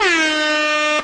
0020_air-horn-1-minute.mp3